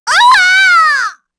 Shamilla-Vox_Damage_kr_03.wav